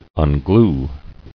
[un·glue]